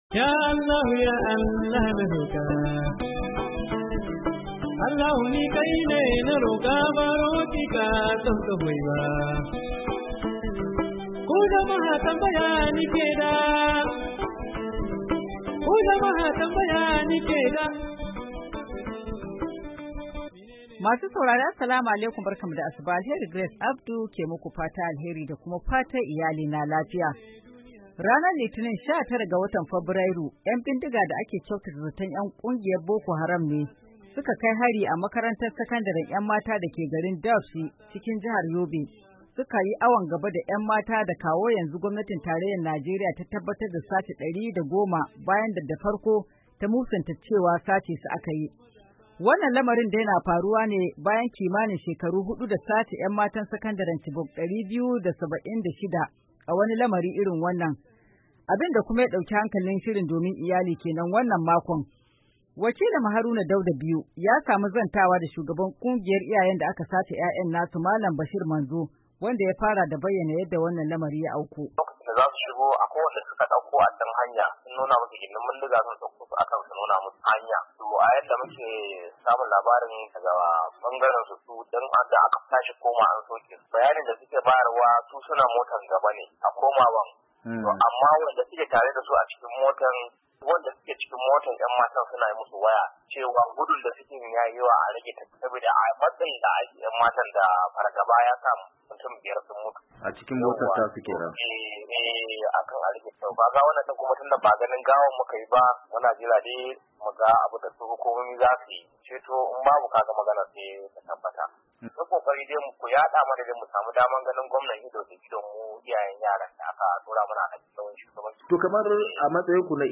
Rahoton sace 'yammatan Dapchi-10:30"